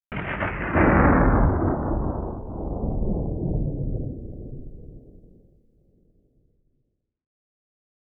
Roland.Juno.D _ Limited Edition _ GM2 SFX Kit _ 14.wav